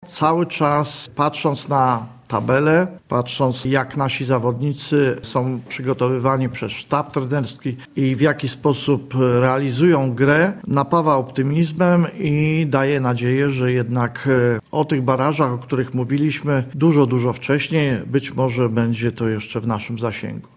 Na specjalnej konferencji prasowej władze Sportowej Spółki Akcyjnej Stal Stalowa Wola zaprezentowały informacje na temat spraw związanych z finansami i polityką kadrową w klubie.